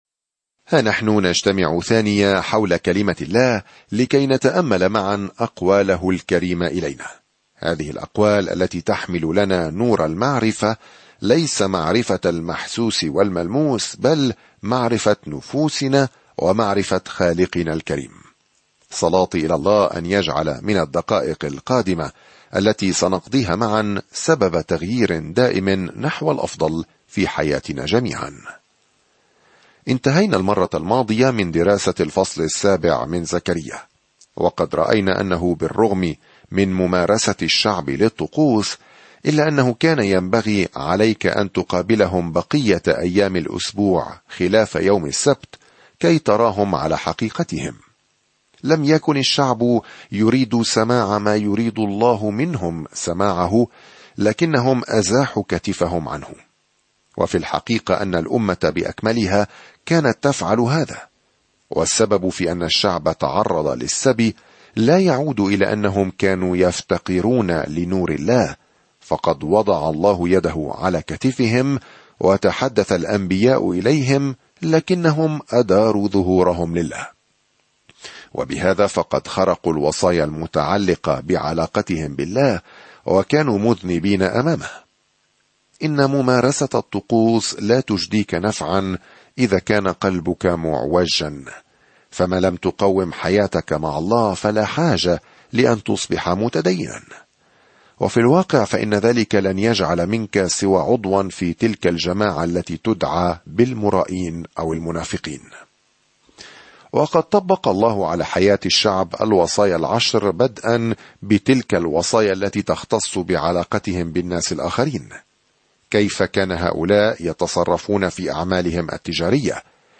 الكلمة زَكَريَّا 1:8-8 يوم 17 ابدأ هذه الخطة يوم 19 عن هذه الخطة يشارك النبي زكريا رؤى وعود الله بمنح الناس رجاء بالمستقبل ويحثهم على العودة إلى الله. سافر يوميًا عبر زكريا وأنت تستمع إلى الدراسة الصوتية وتقرأ آيات مختارة من كلمة الله.